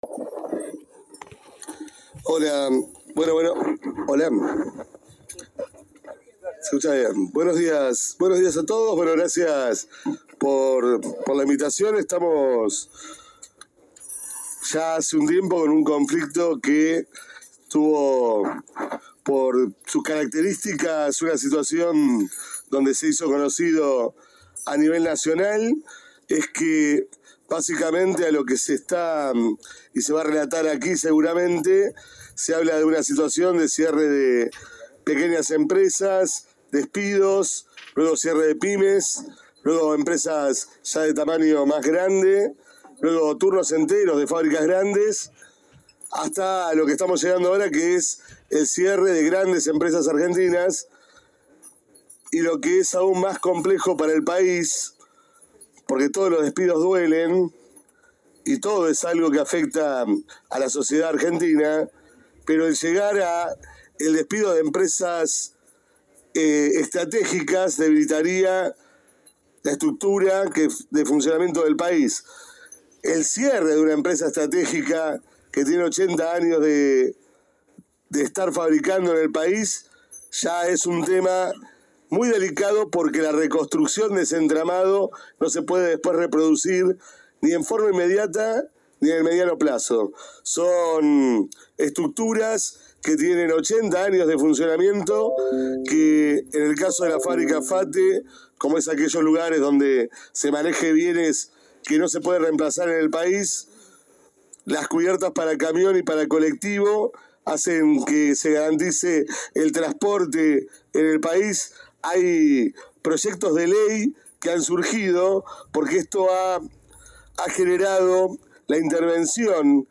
COMISIÓN DE ECONOMÍA – CÁMARA DE DIPUTADOS DE LA NACIÓN